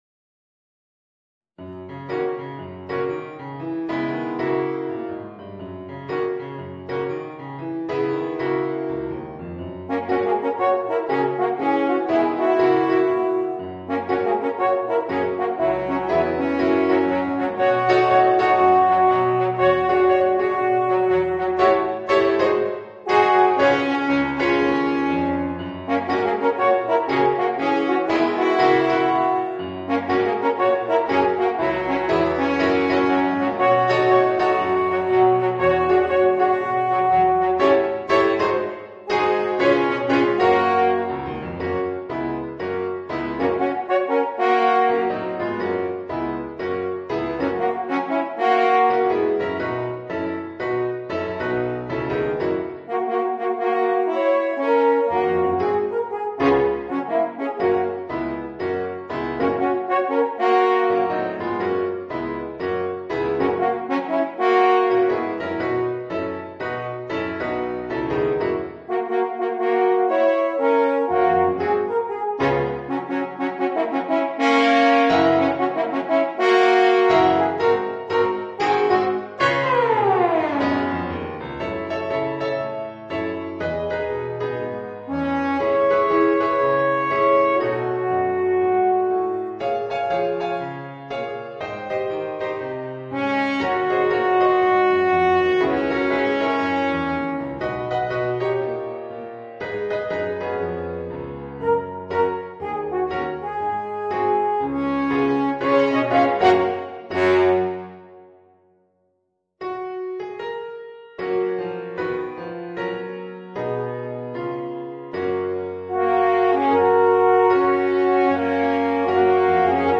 Voicing: 2 Alphorns and Piano